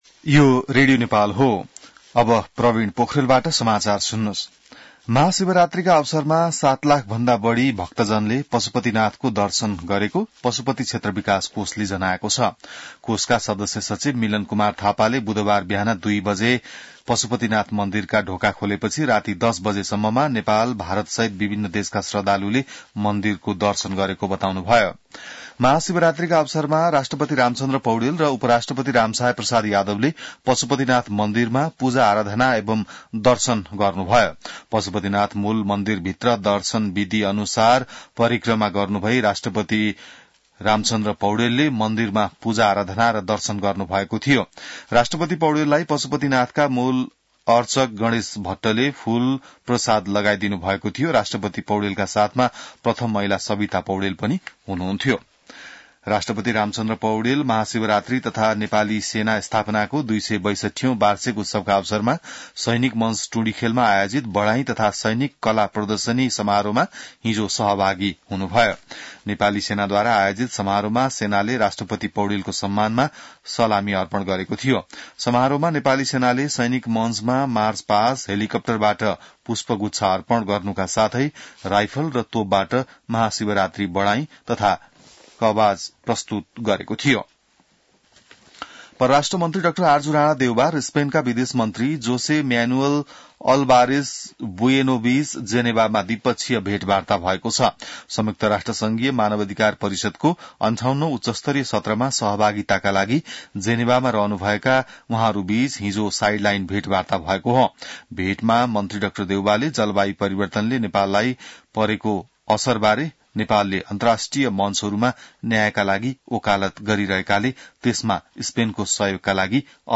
बिहान ६ बजेको नेपाली समाचार : १६ फागुन , २०८१